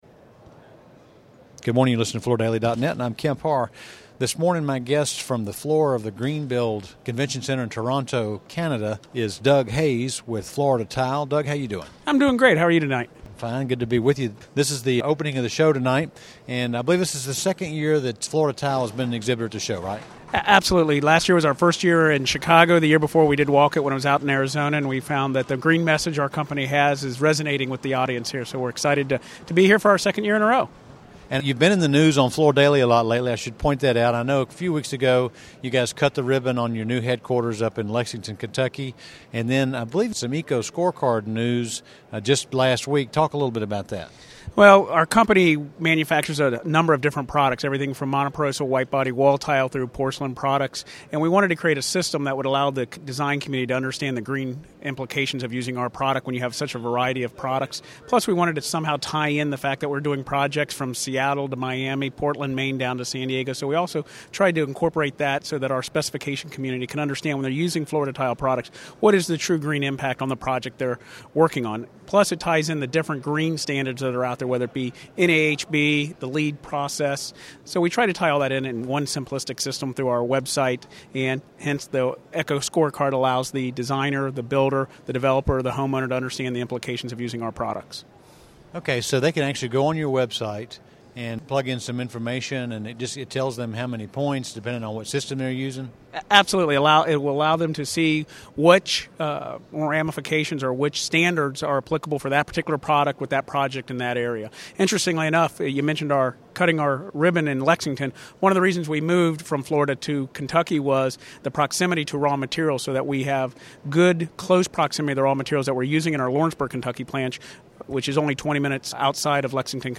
The interview was recorded at Greenbuild.